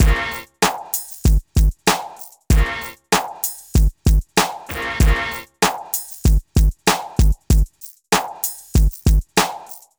Night Rider - Beat.wav